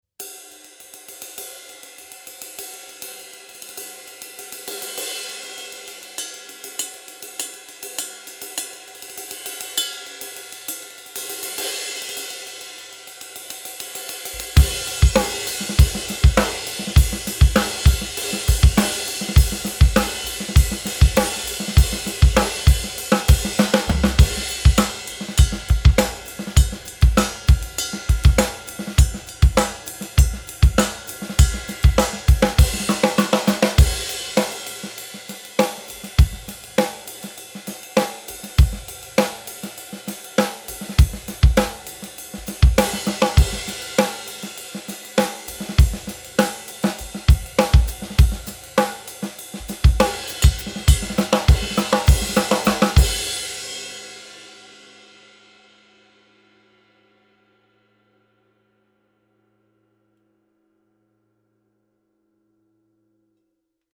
Like all A Custom cymbals, this ride features symmetrical hammering, tight lathing, and a brilliant finish. Its medium-thin weight is lighter than other A Custom rides.
Zildjian-A-Custom-Anniversary-ride.mp3